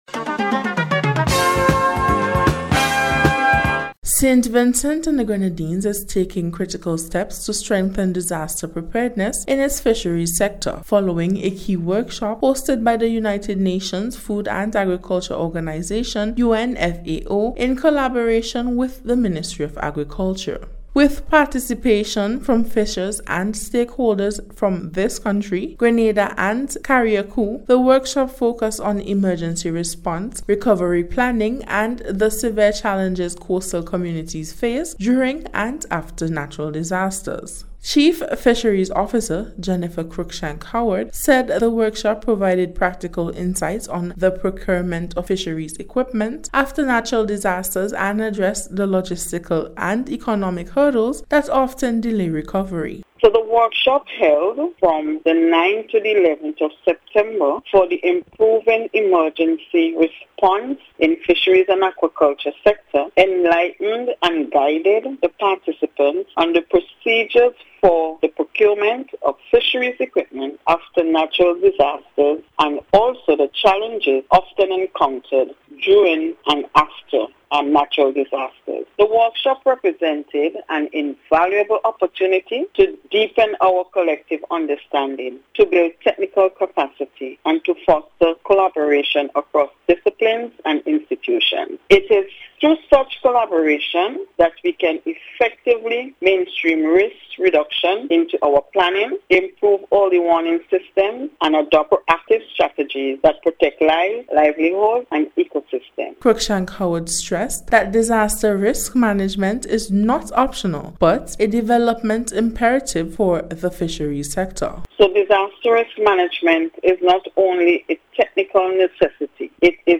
FISHERIES-DISASTER-RESPONSE-REPORT.mp3